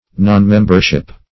Nonmembership \Non*mem"ber*ship\, n.
nonmembership.mp3